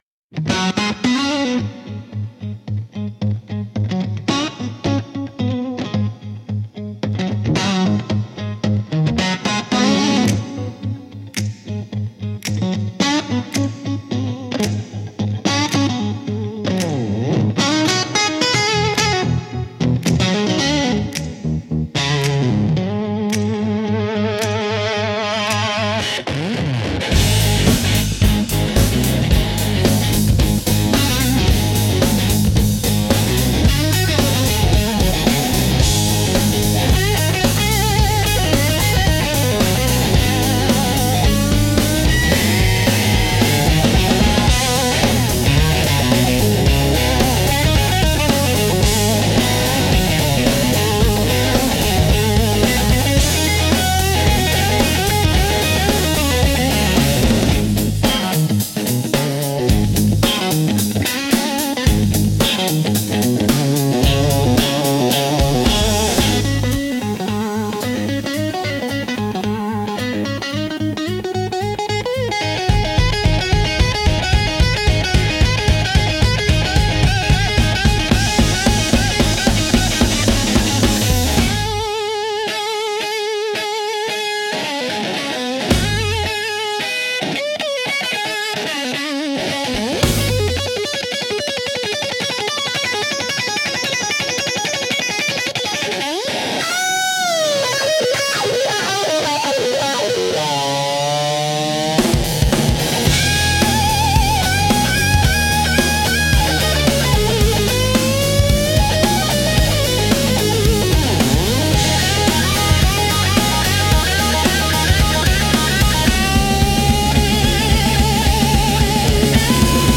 Instrumental - Tension at the County Line